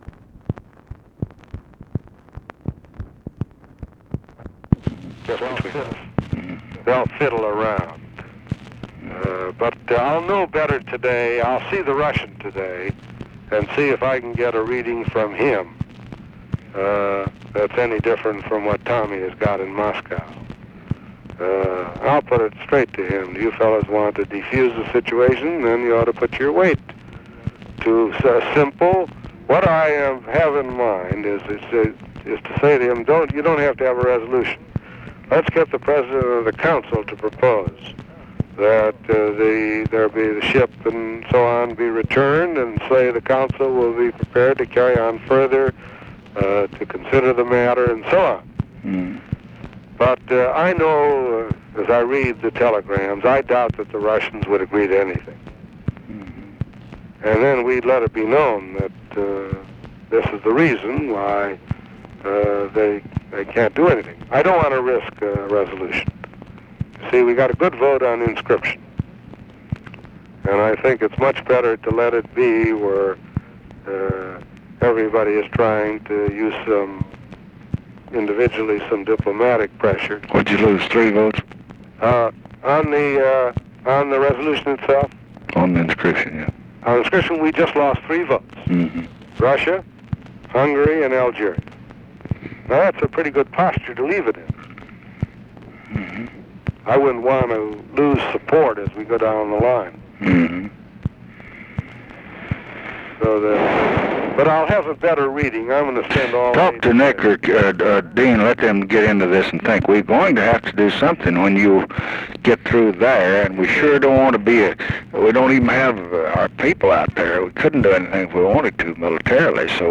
Conversation with ARTHUR GOLDBERG, January 28, 1968
Secret White House Tapes